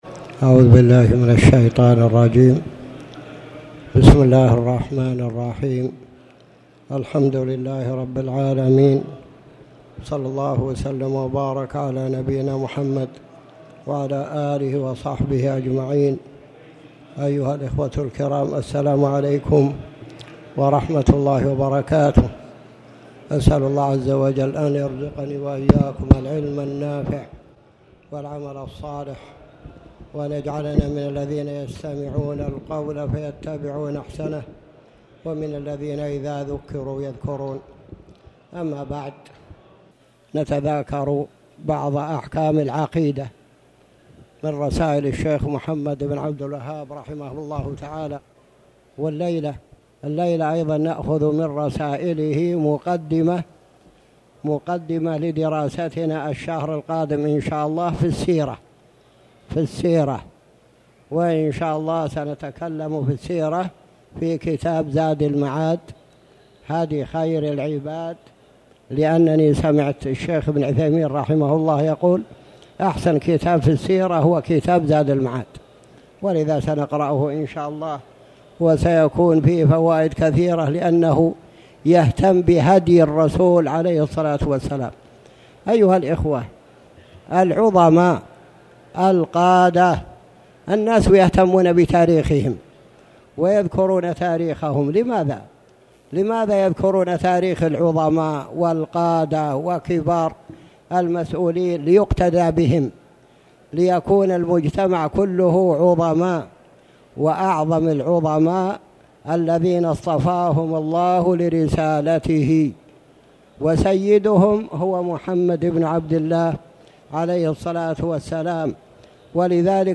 تاريخ النشر ٢٥ جمادى الآخرة ١٤٣٩ هـ المكان: المسجد الحرام الشيخ